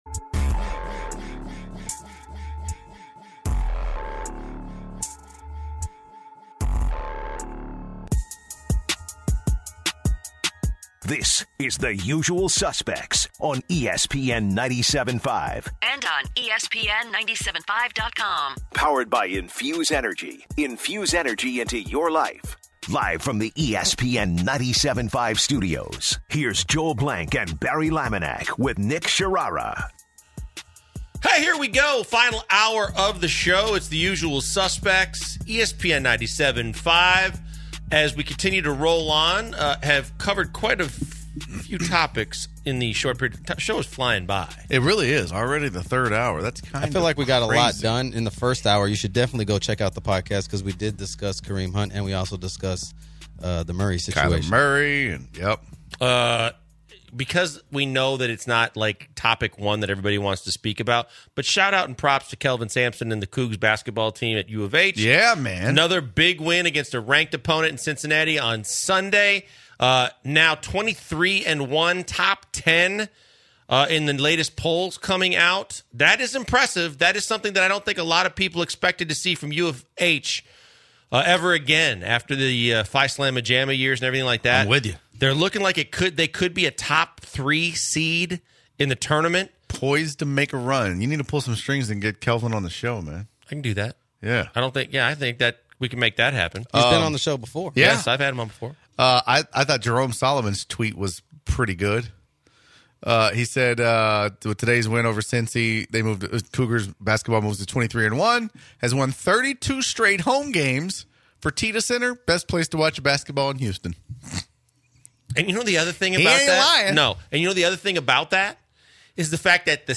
In the final hour of the show the guys talk about the UH basketball season being inside the top 10 for the first time since the Phi-Slama-Jamma days as the team looks ahead to Temple and Cincinnati this week. Then the guys take some calls about the AAF and discuss the strange rule differences between the NFL and the spring league.